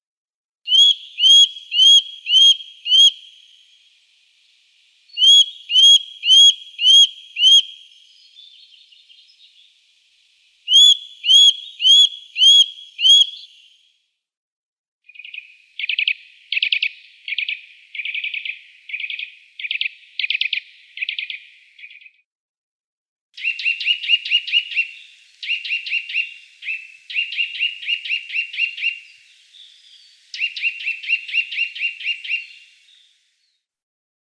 Подборка включает разные варианты голосов, записанных в естественной среде обитания.
Голос поползня